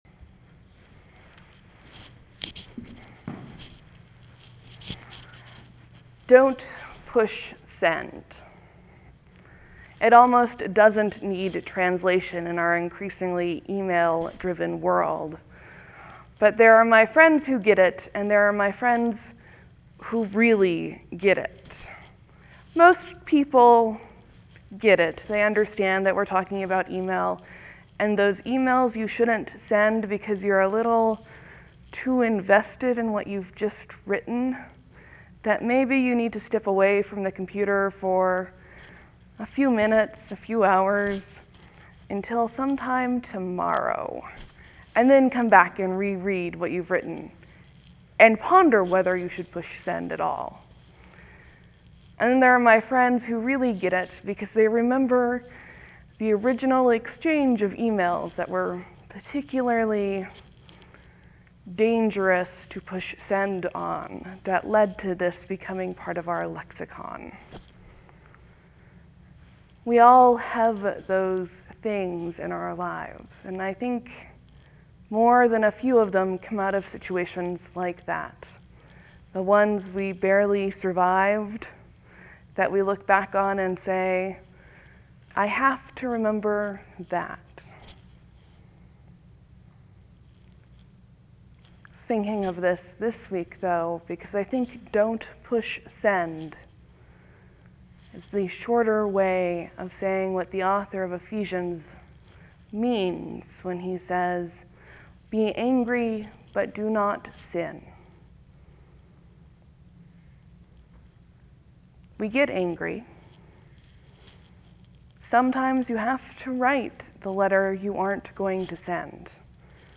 (There will be a few moments of silence before the sermon starts. Thank you for your patience.)